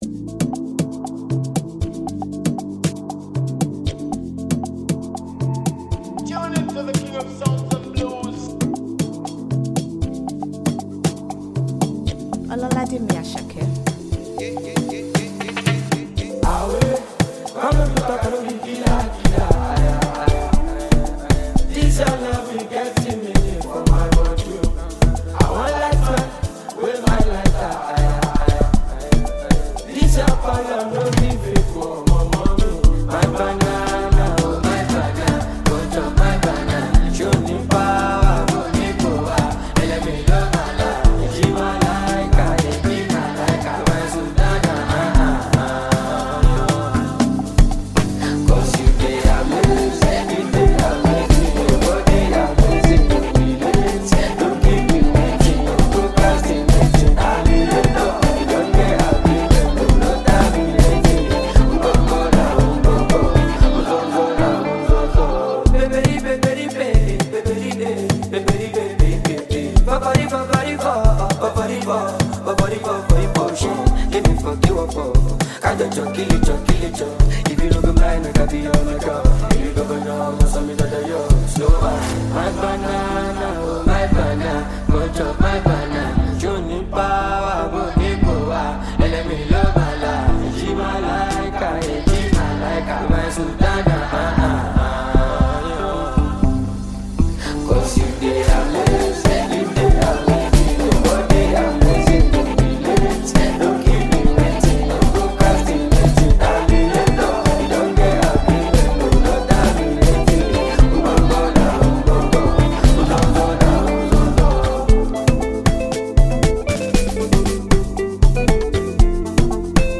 smooth vocals and the catchy hook
blends Afrobeat with contemporary sounds
With its upbeat tempo and catchy sounds